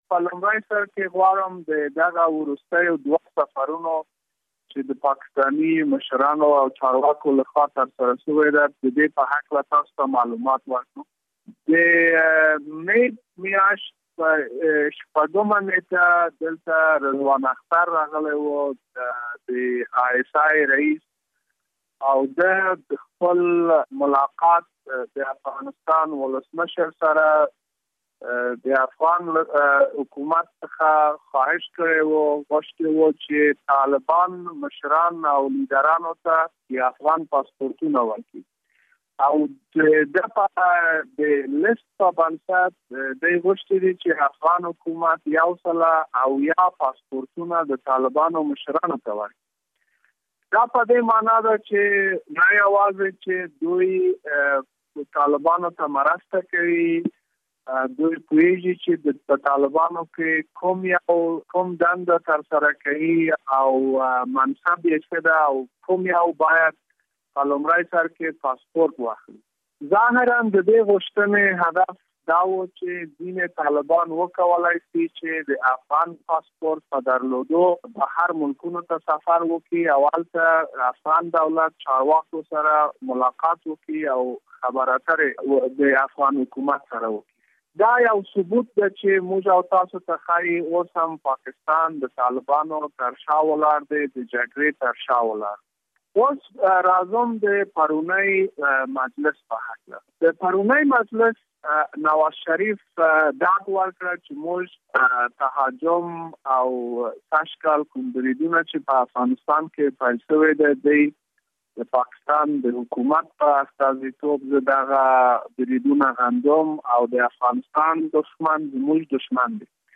له امر الله صالح سره مرکه